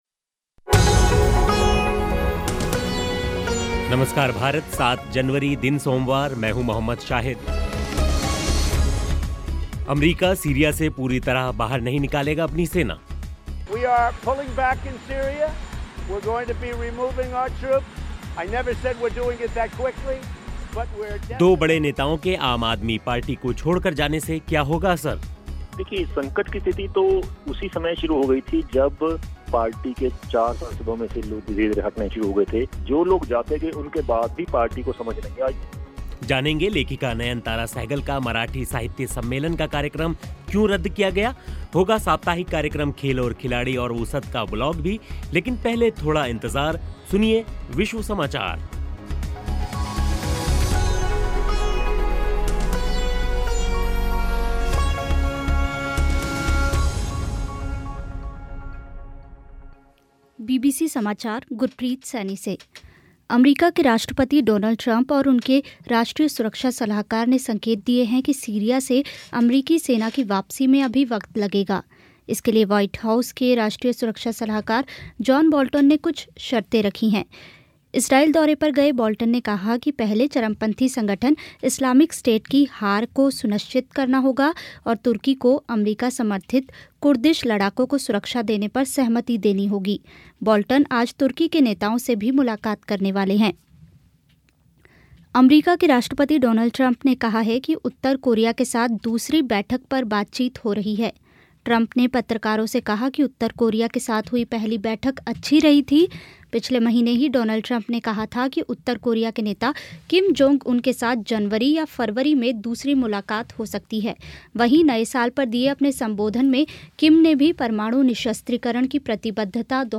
लेकिन पहले सुनिए विश्व समाचार.